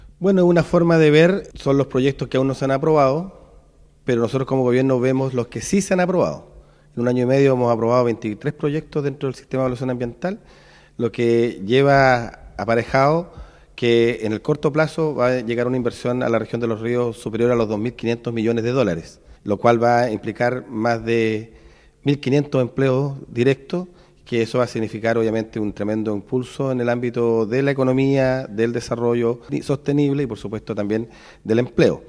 El delegado presidencial, Jorge Alvial, aseguró que como Gobierno les interesa poner en la mesa lo que sí se ha aprobado, que dará a su juicio un enorme impulso a la economía, desarrollo sostenible y el empleo.